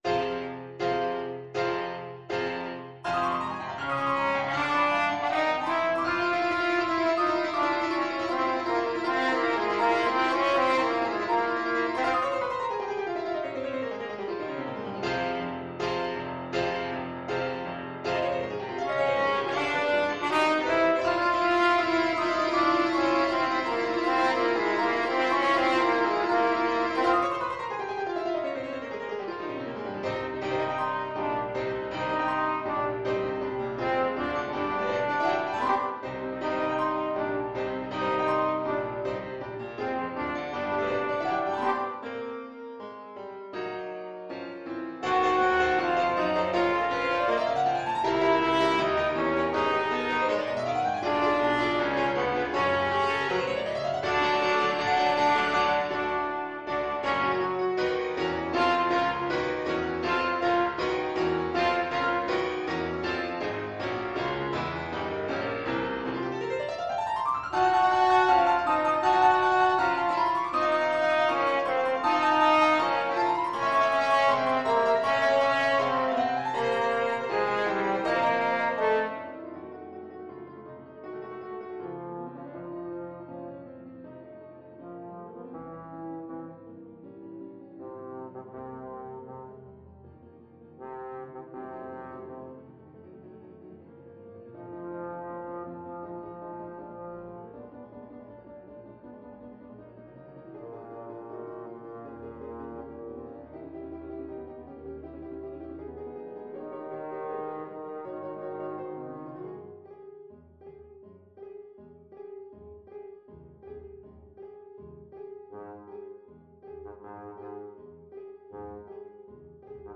Trombone version
4/4 (View more 4/4 Music)
Allegro agitato (=80) (View more music marked Allegro)
Ab3-G5
Classical (View more Classical Trombone Music)